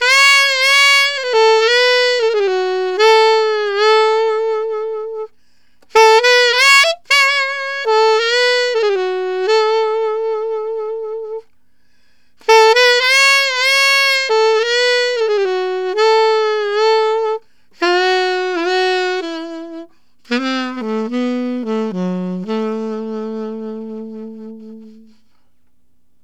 Alto One Shot in A 01.wav